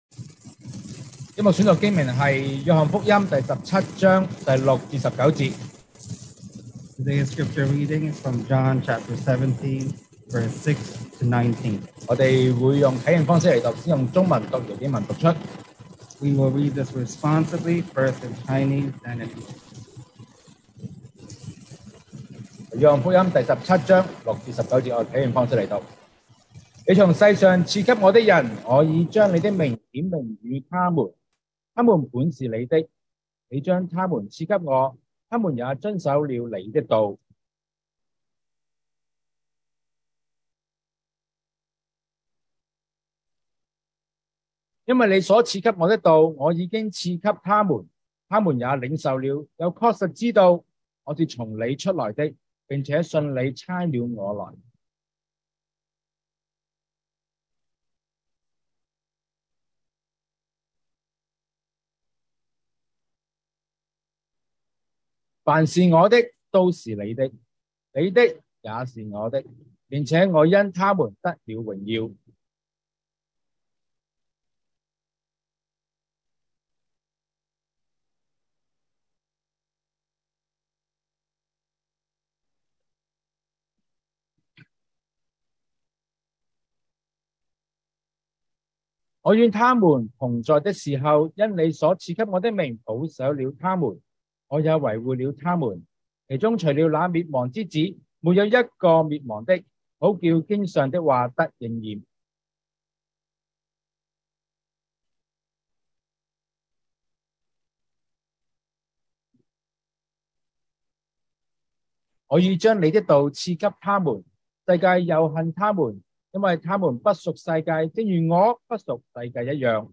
2022 sermon audios
Service Type: Sunday Morning